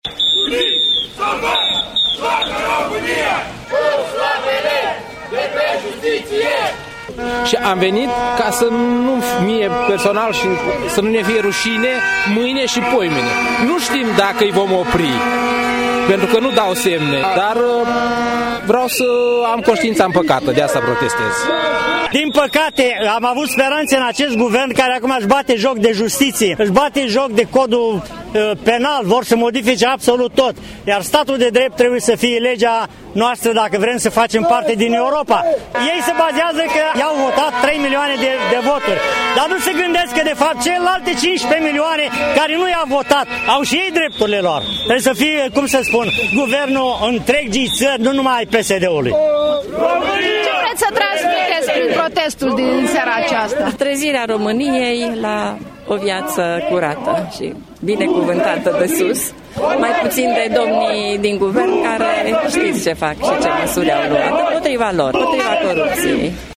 Deşi la Galaţi ninge puternic şi este frig, peste 500 de gălăţeni s-a adunat, în această seară, în faţa Prefecturii Galaţi, scandând mesaje împotriva Guvernului şi împotriva PSD-ului.